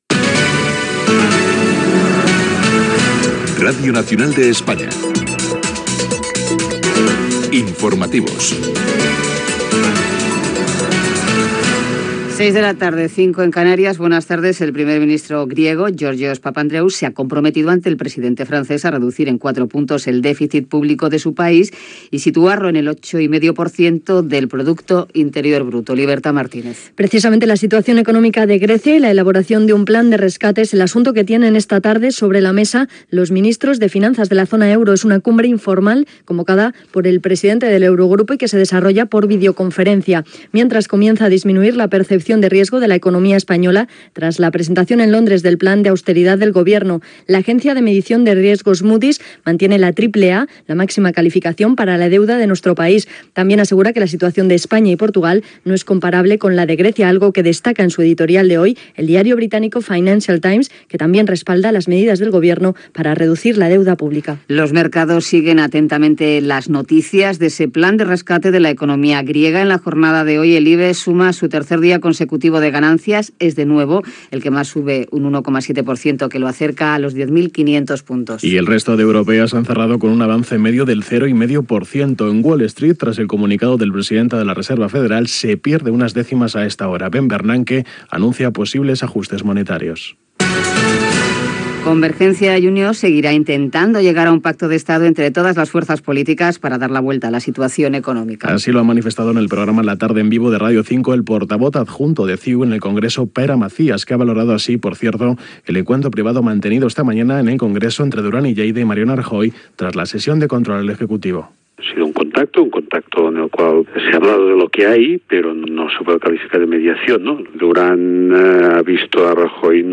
Careta del programa, rescat de l'economia grega, situació dels mercats financers, possible pacte d'Estat entre Convergència i Unió i el Partido Popular, sessió de control al govern de la Generalitat, la Llei d'Estrangeria i el Ministerio del Interior, Israel, esports i careta
Informatiu